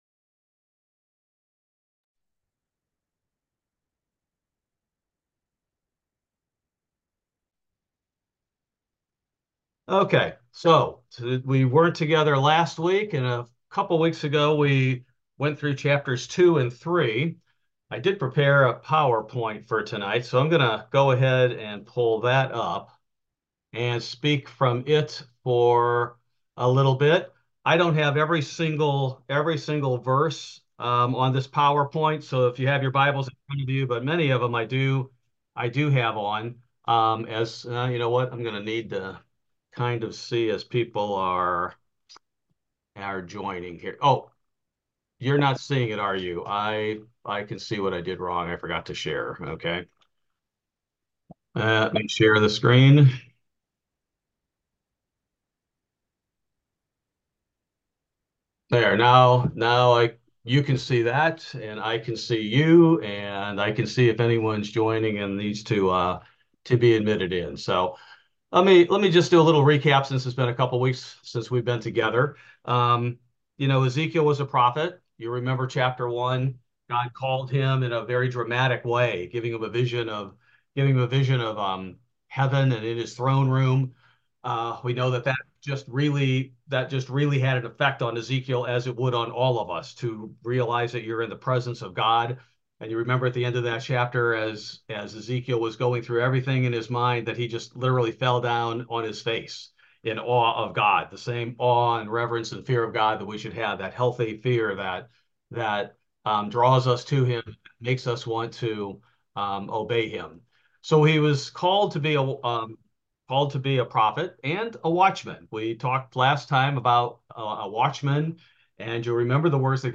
Bible Study: April 17, 2024